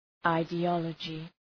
{,aıdı’ɒlədʒı}